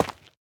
Minecraft Version Minecraft Version snapshot Latest Release | Latest Snapshot snapshot / assets / minecraft / sounds / block / dripstone / break2.ogg Compare With Compare With Latest Release | Latest Snapshot
break2.ogg